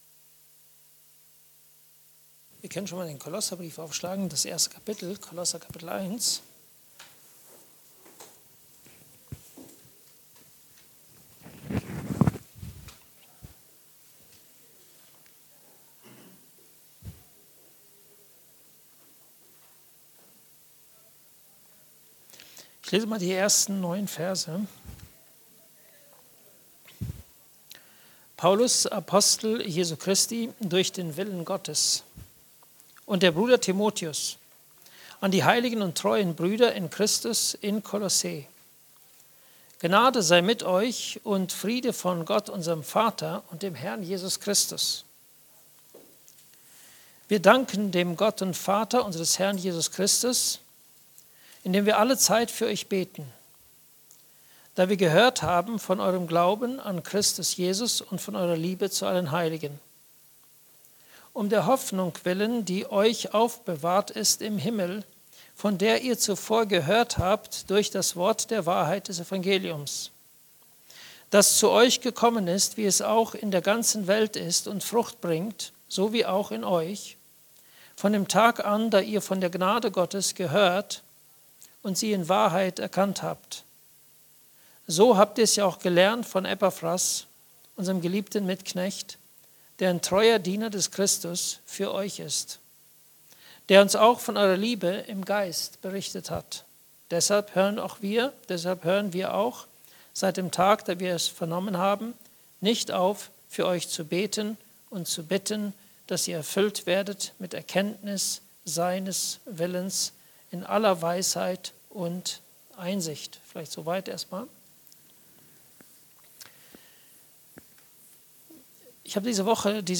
Grundlage unseres Bittens (Andacht Gebetsstunde)